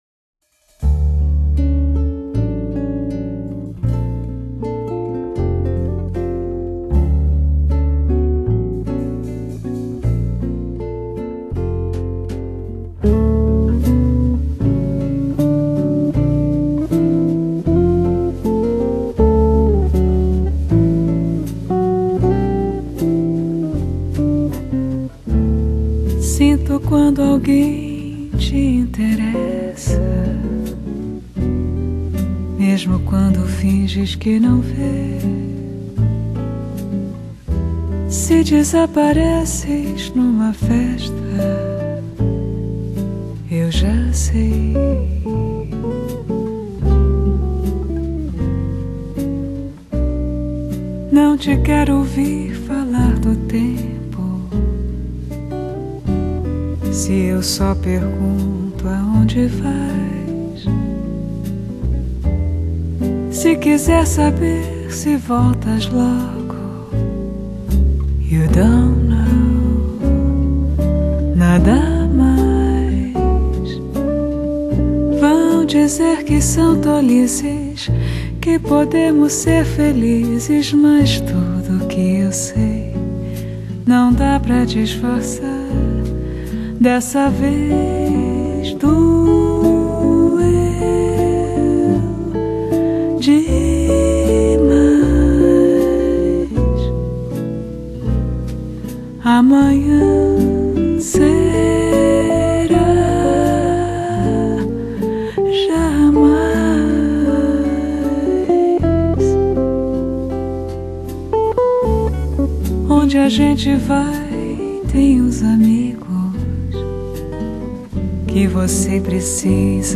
风格流派：Bossa Nova
经典灵魂乐的翻唱，洋溢着六、七十年代的经典情怀。
配乐上以吉他为核心，除了她自己的演奏，还请来三位巴西籍的吉他手参与录制。